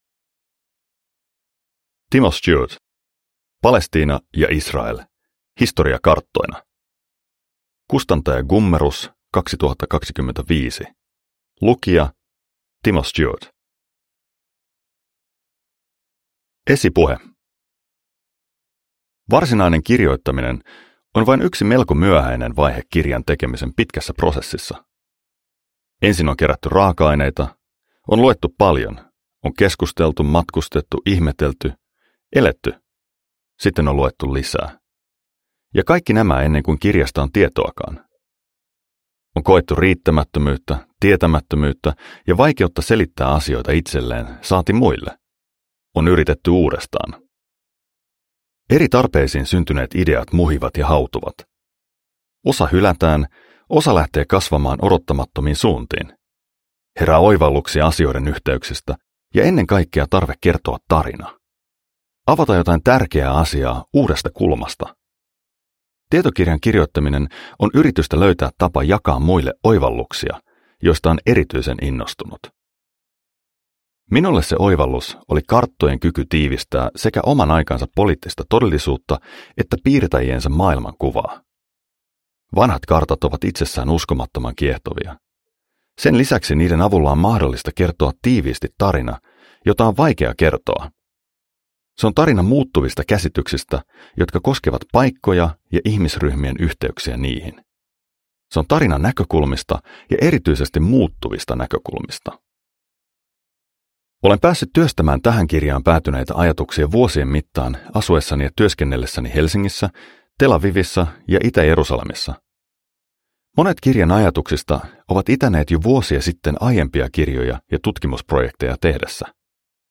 Palestiina ja Israel – Ljudbok